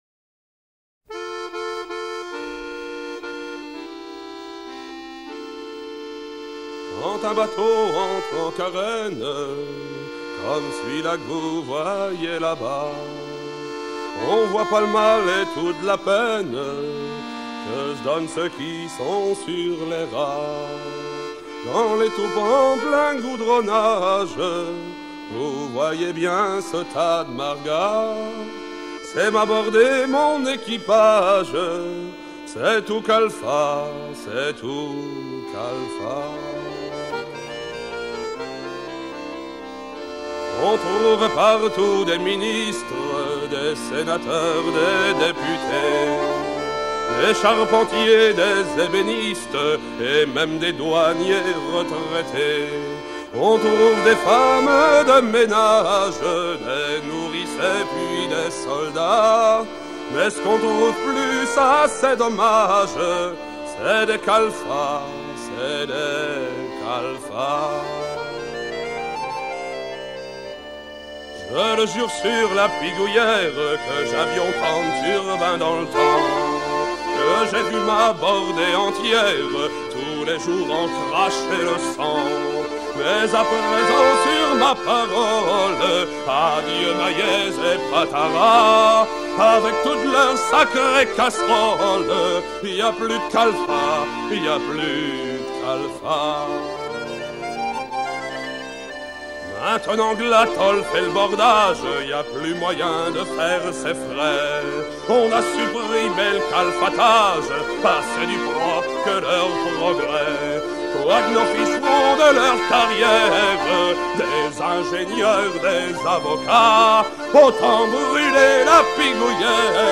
sur un timbre
Pièce musicale éditée